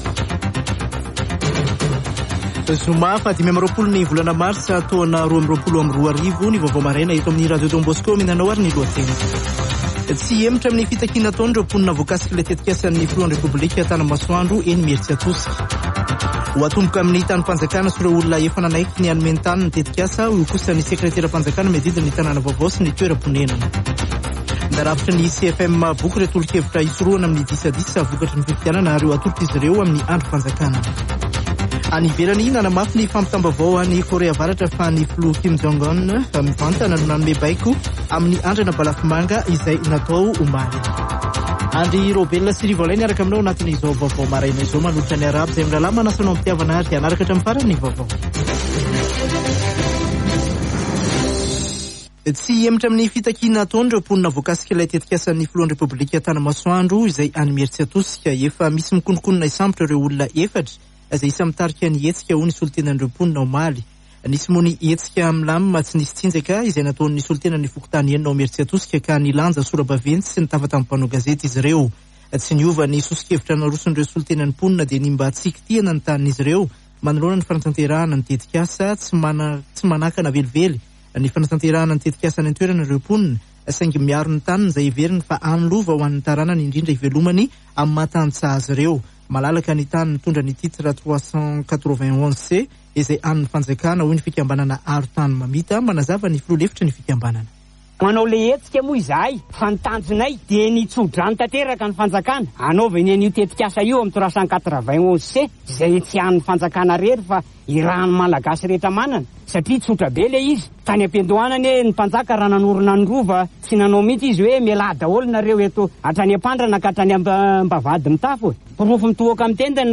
[Vaovao maraina] Zoma 25 marsa 2022